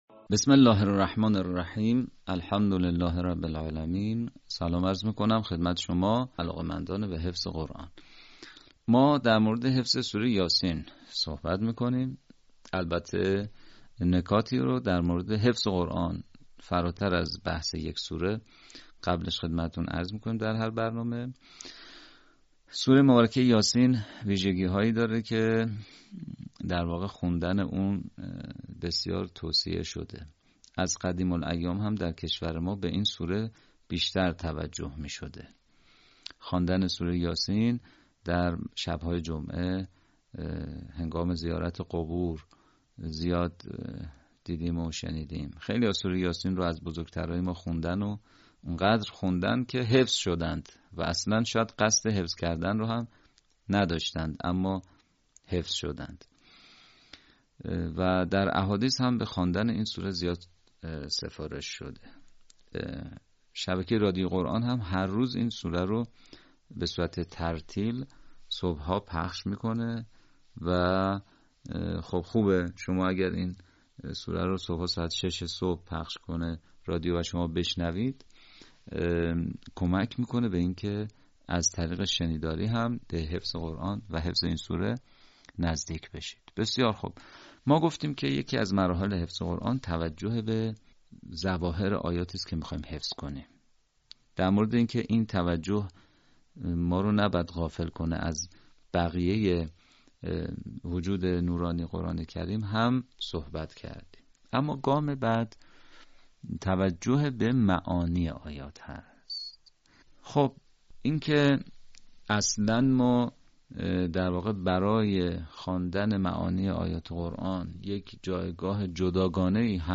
به همین منظور مجموعه آموزشی شنیداری(صوتی) قرآنی را گردآوری و برای علاقه‌مندان بازنشر می‌کند.
آموزش حفظ قرآن